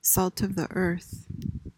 PRONUNCIATION:
(SALT uhv thuh UHRTH)